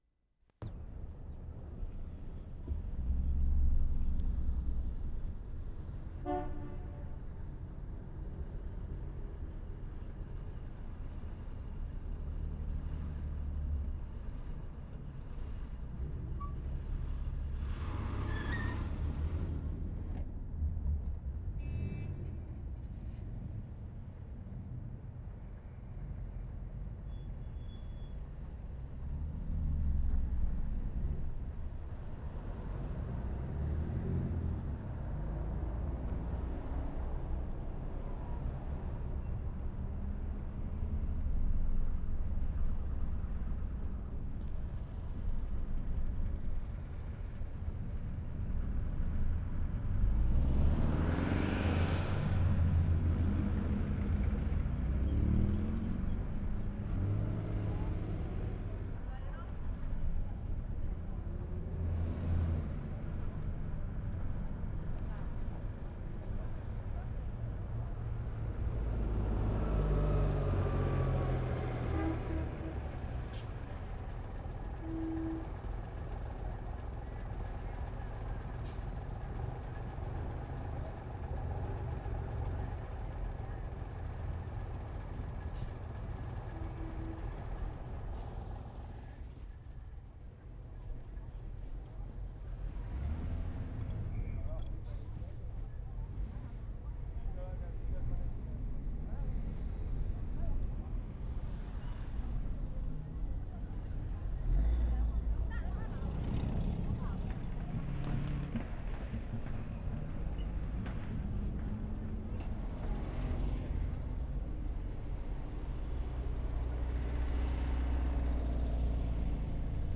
CHINATOWN Sept. 24, 1972
DRAGON DANCE 6'45"
Various takes from different positions of the Dragon Dance. Traffic for the most part has been cordoned off (compare Reel 5 for normal ambience). Mixture of Chinese, English and other languages. Drums and firecrackers are almost constant.
3. Begins in moving car.
0'06" car horn.
1'35" change direction of microphones.
2'40" motorbike.
3'15" deep rumble from car driving on cobblestones.
3'50" voices, stray firecrackers, very distant drums.
*4'45" children blowing very high whistles.
5'00" police whistle.